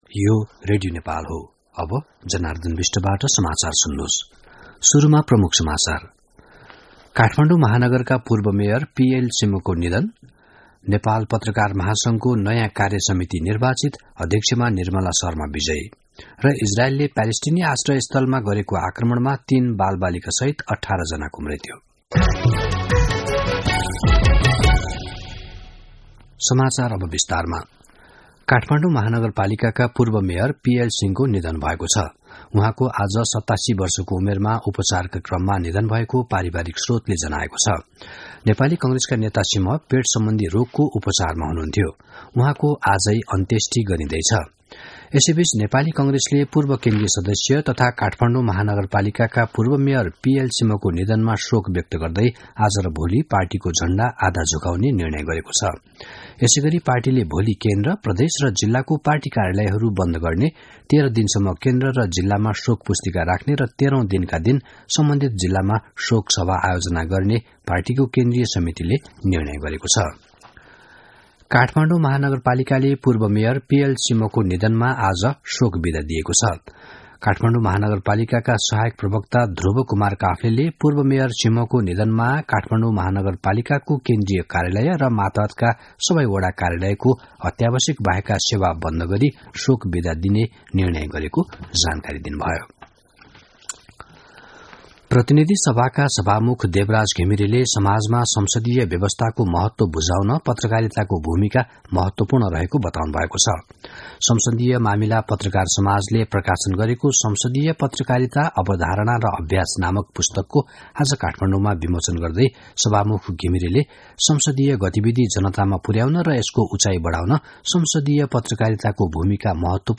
दिउँसो ३ बजेको नेपाली समाचार : २ पुष , २०८१
3-pm-nepali-news-1-9.mp3